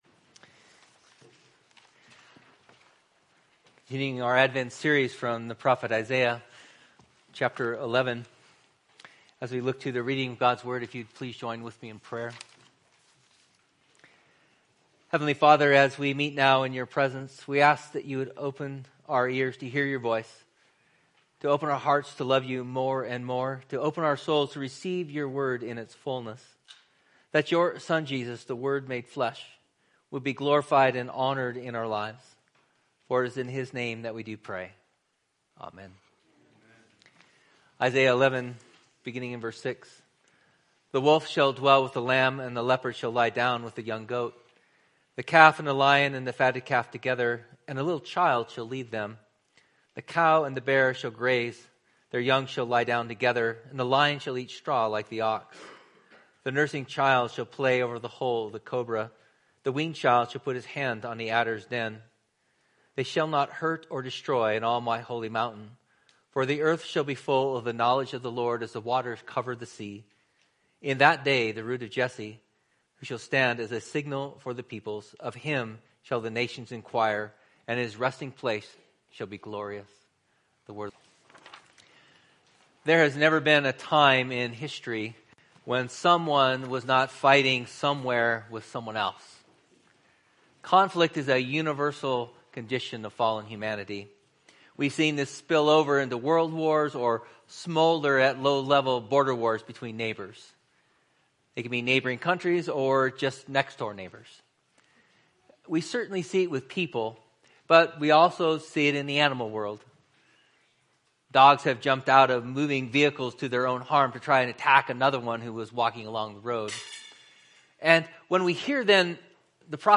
Type Morning